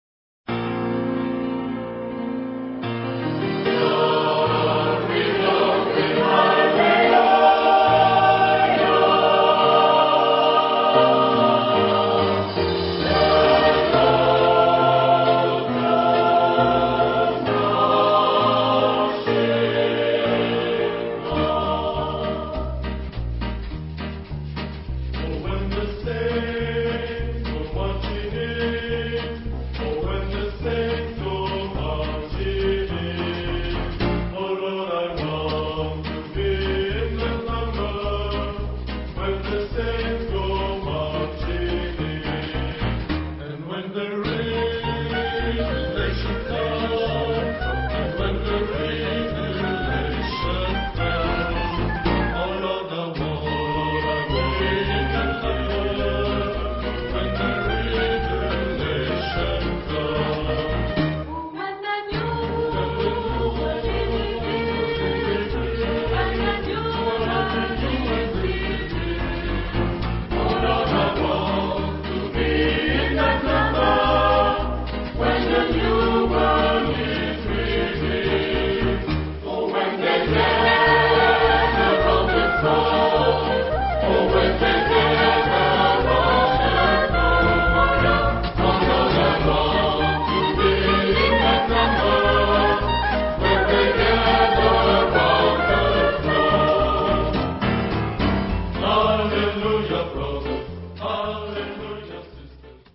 Genre-Style-Form: Secular ; Sacred ; Popular
Type of Choir: SSATTB  (6 mixed voices )
Instruments: Piano (1)
Tonality: E flat major